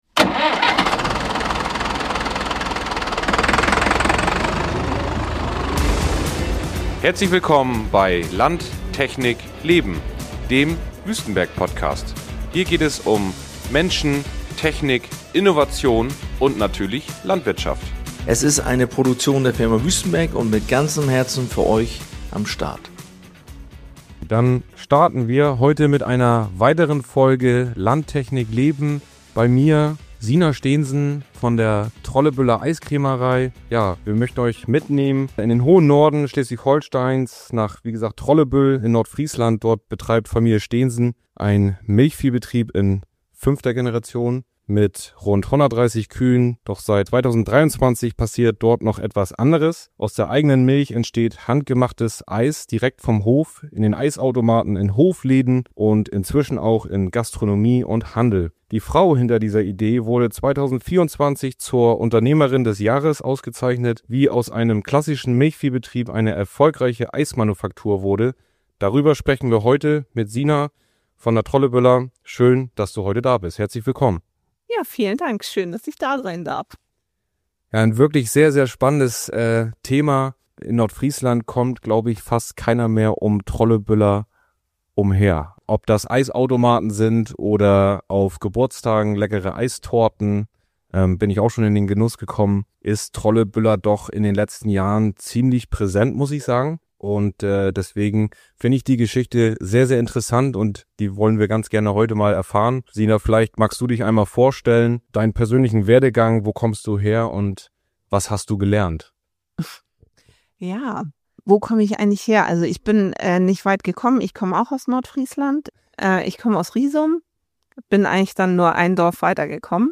In dieser Folge erzählt sie, wie aus einer Idee ein erfolgreiches Hofprojekt wurde, welche Herausforderungen auf dem Weg lagen und warum Wertschöpfung auf landwirtschaftlichen Betrieben immer wichtiger wird. Ein ehrliches Gespräch über Mut, Unternehmertum und die Zukunft der Landwirtschaft.